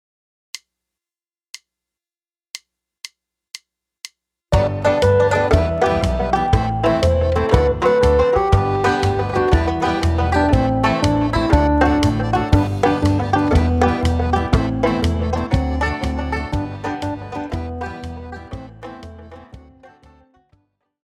Piosenki dla dzieci
Podkład w wysokiej jakości w wersjach mp3 oraz wav